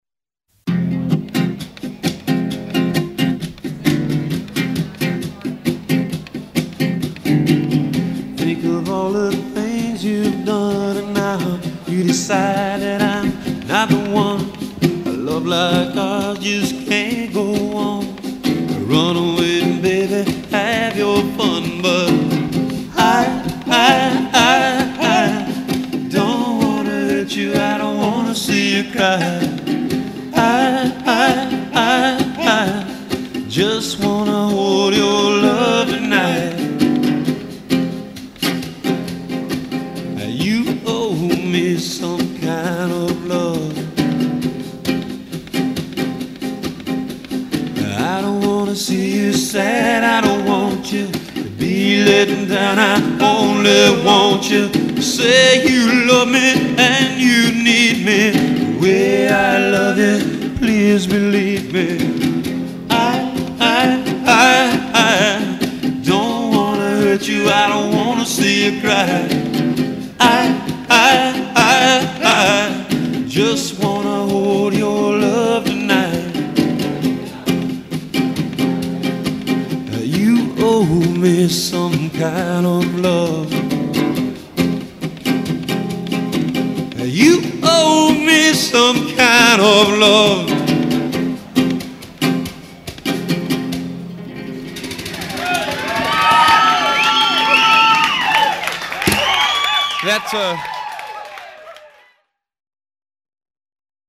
ukelele
percussionist